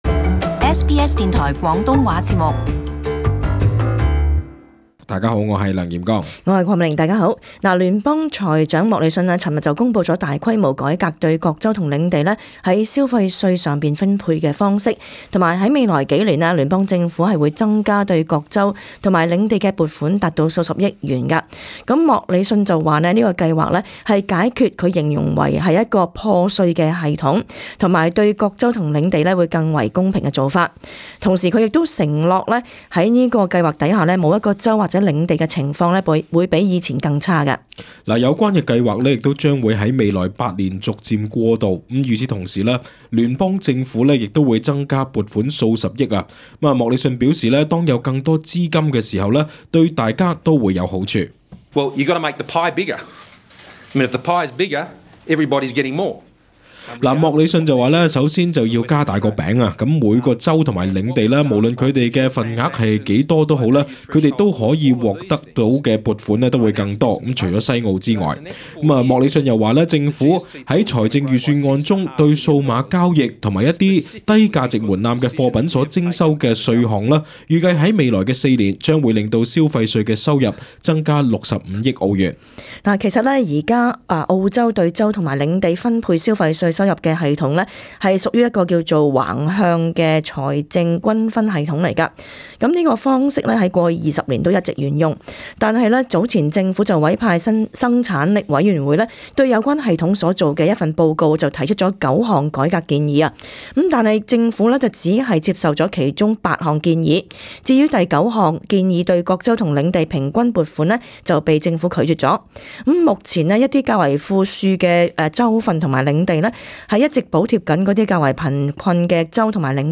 【时事报导】财长公布全新消费税改革方案